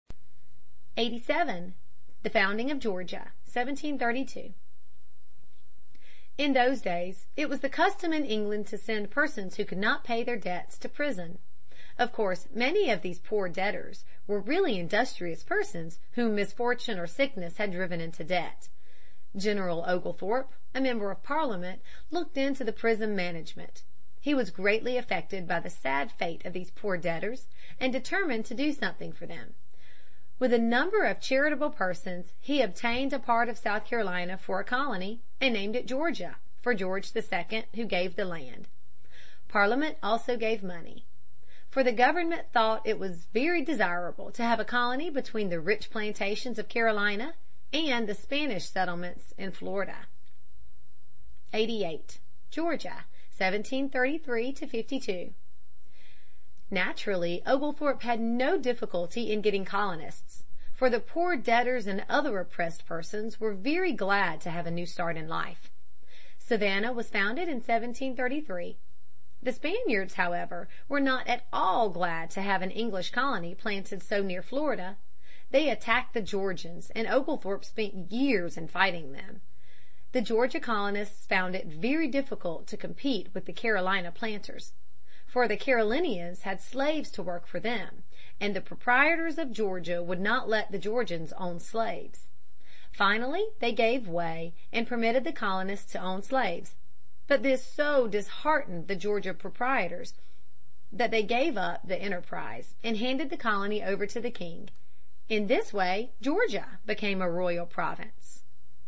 在线英语听力室美国学生历史 第28期:殖民地的发展(3)的听力文件下载,这套书是一本很好的英语读本，采用双语形式，配合英文朗读，对提升英语水平一定更有帮助。